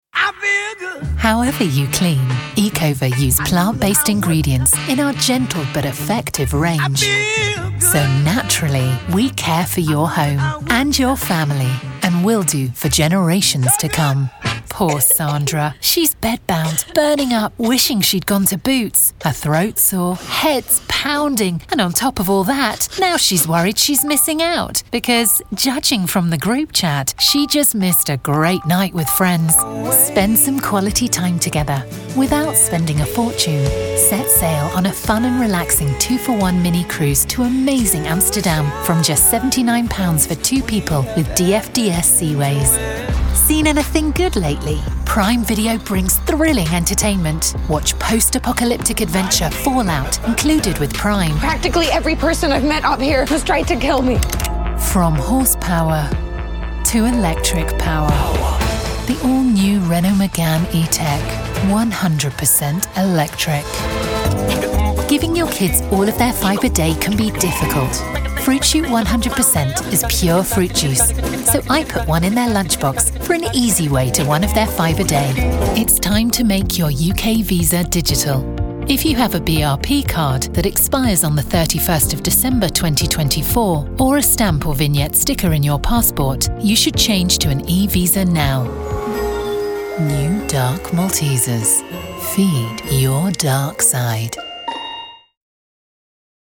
Inglés (Británico)
Natural, Amable, Cálida, Empresarial, Versátil
Comercial